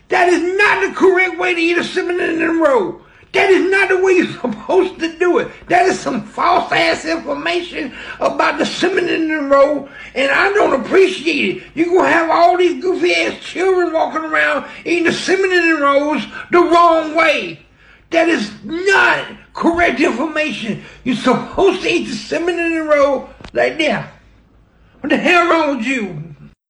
Talking_Male_Mad_Man.wav